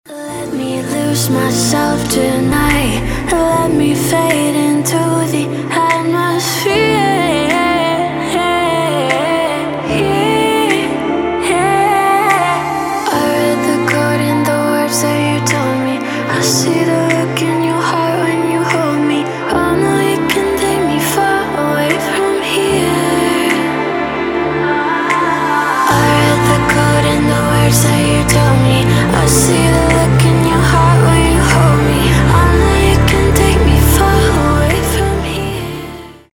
• Качество: 320, Stereo
мелодичные
нарастающие
Melodic dubstep
Trap
красивый женский голос